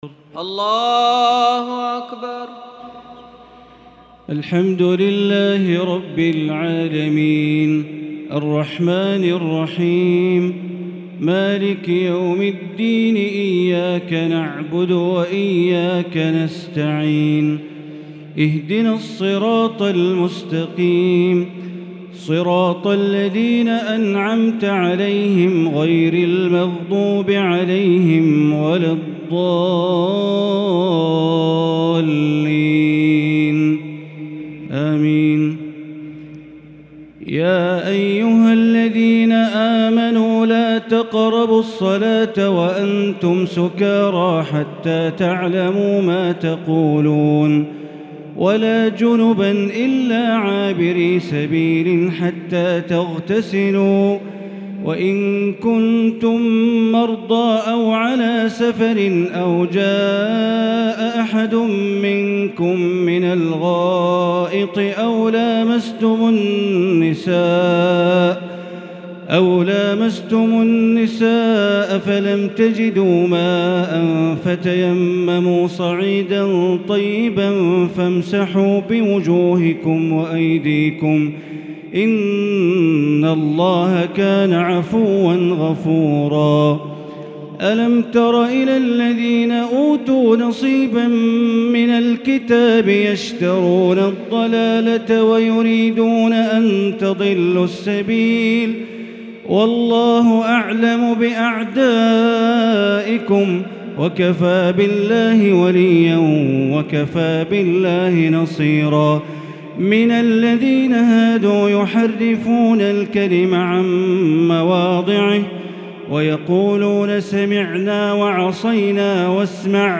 تراويح ليلة 6 رمضان 1443هـ من سورة النساء {43-87} Taraweeh 6st night Ramadan 1443H Surah An-Nisaa > تراويح الحرم المكي عام 1443 🕋 > التراويح - تلاوات الحرمين